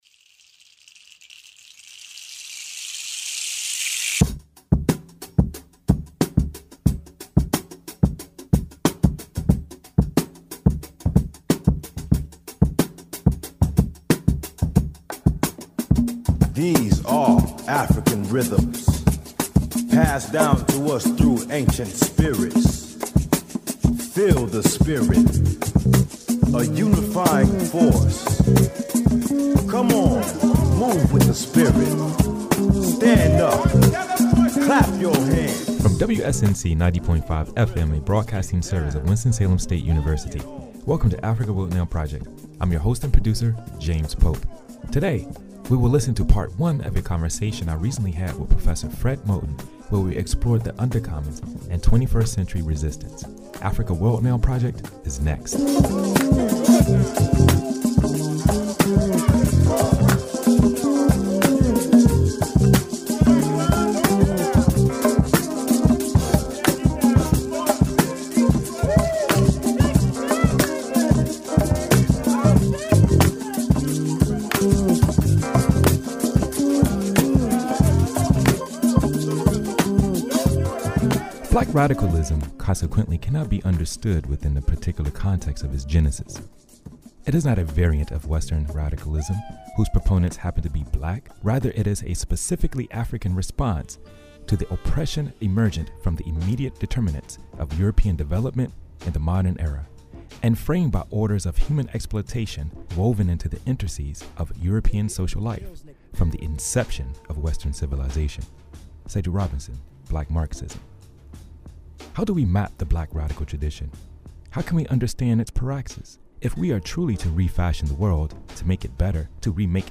interviews with and presentations from artists, activists, scholars, thinkers, practitioners, and other stake holders throughout the Africana world